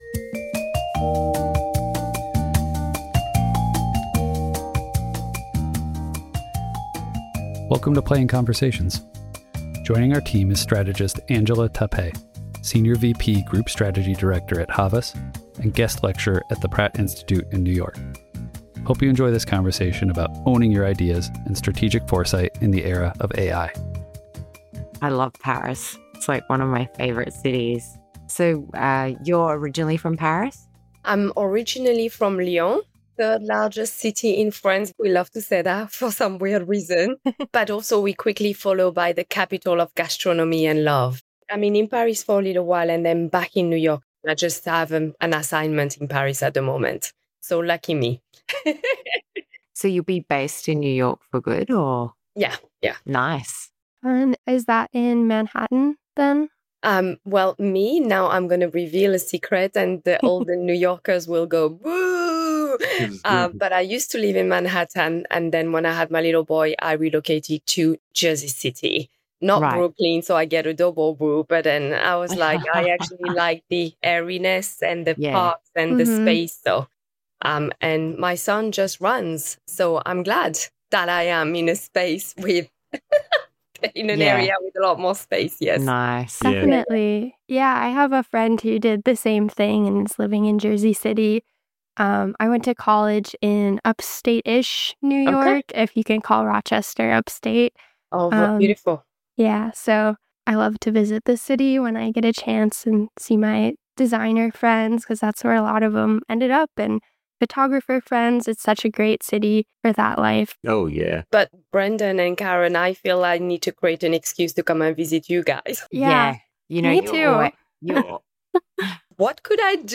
Each episode is an unscripted, authentic conversation about creativity, design, and the processes that drive our work, We explore what inspires us, the challenges we face, and how we keep things fun while getting the job done.